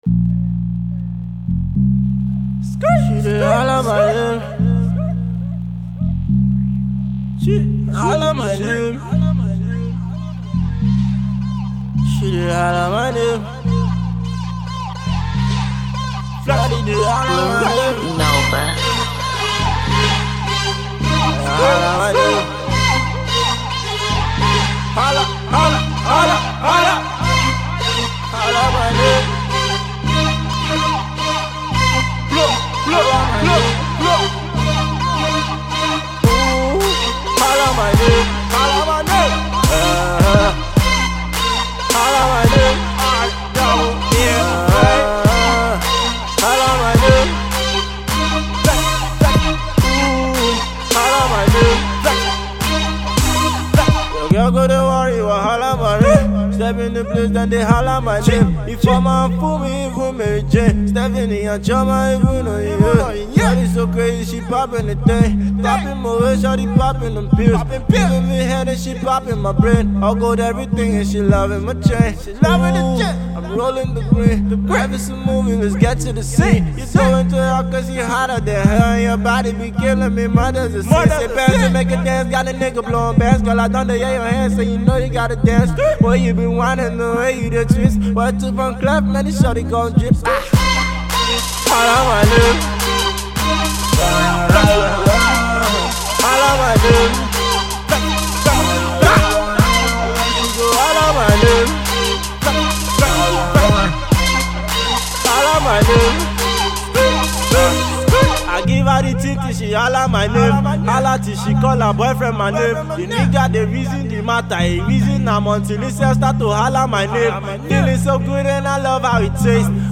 borrowed from American Trap Music